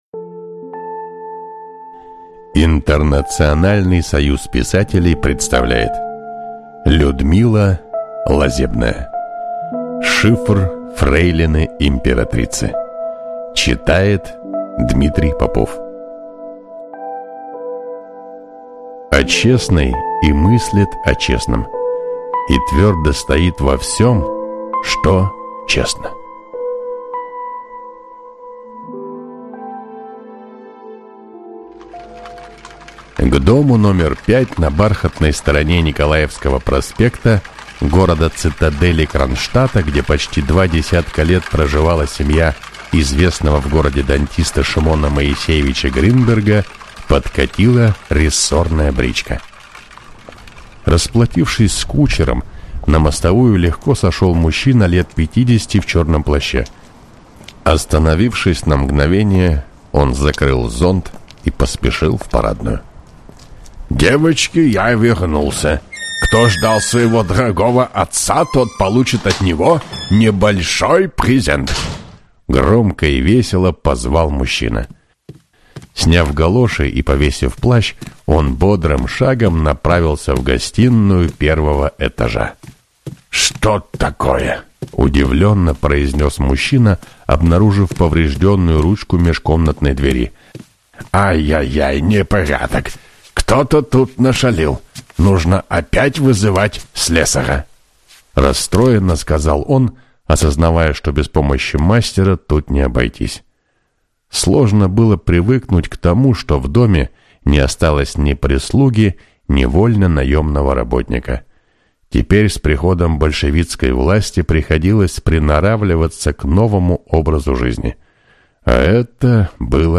Аудиокнига Шифр фрейлины императрицы | Библиотека аудиокниг